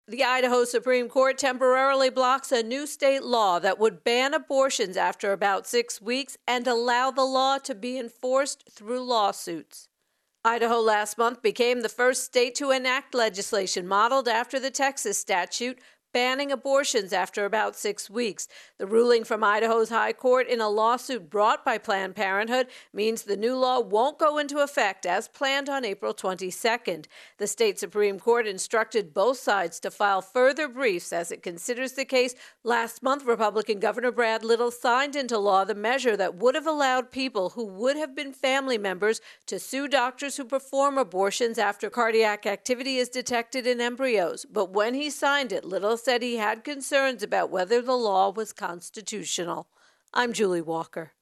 Idaho Abortion Law Blocked intro and voicer